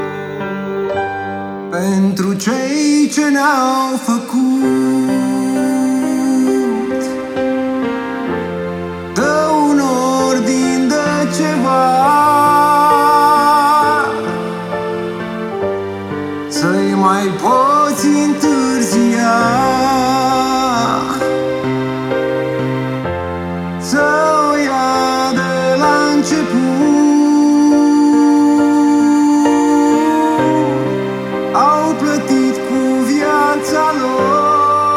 Жанр: Фолк-рок / Альтернатива
# Alternative Folk